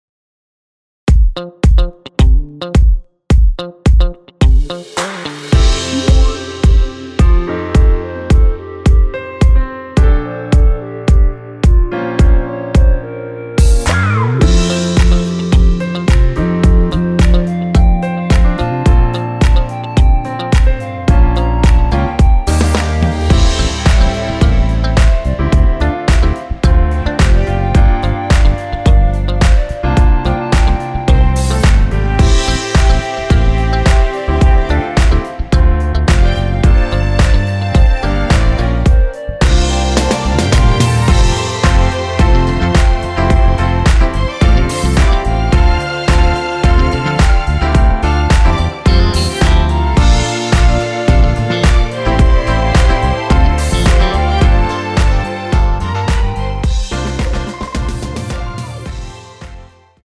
원키에서(+1)올린 멜로디 포함된 MR입니다.(미리듣기 확인)
◈ 곡명 옆 (-1)은 반음 내림, (+1)은 반음 올림 입니다.
멜로디 MR이라고 합니다.
앞부분30초, 뒷부분30초씩 편집해서 올려 드리고 있습니다.
중간에 음이 끈어지고 다시 나오는 이유는